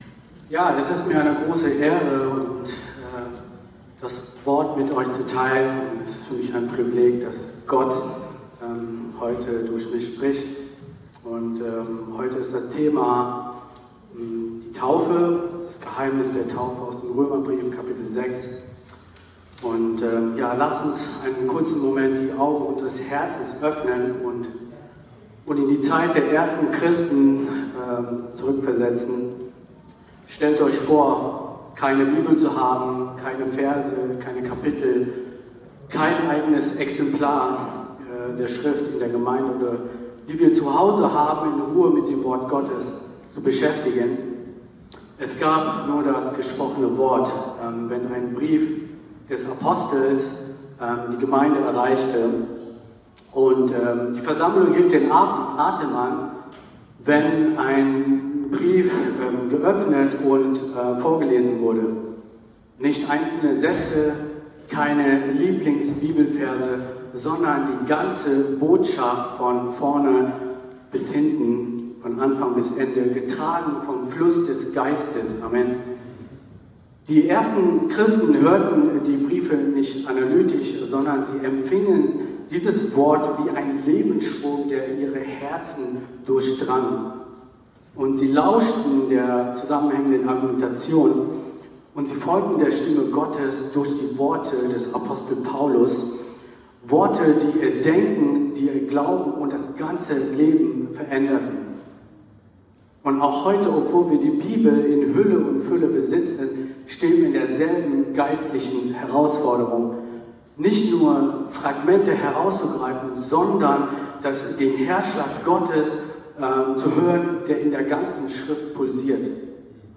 Ziel der Predigt : Eintauchen in den Römerbrief , um das Evangelium als Lebensstrom zu empfangen.